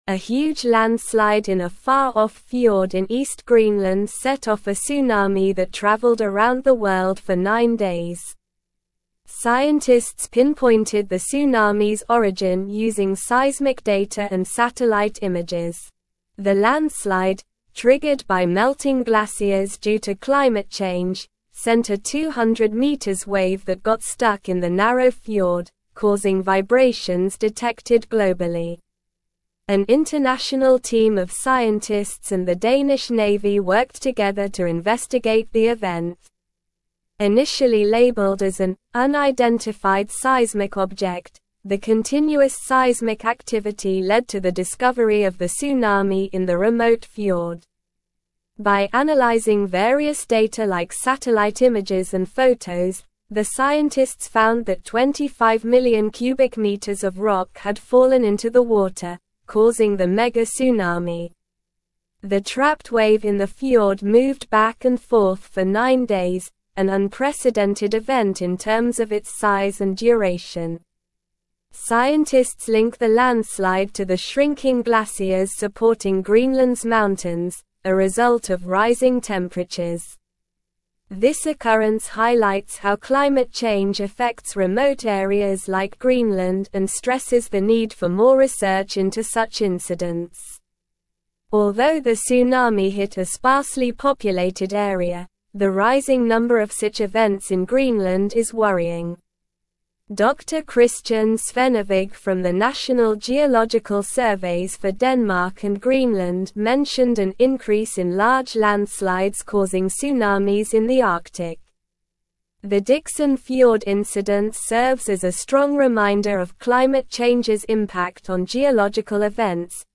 Slow
English-Newsroom-Upper-Intermediate-SLOW-Reading-Greenland-Tsunami-Scientists-Unravel-Cause-of-Mysterious-Tremors.mp3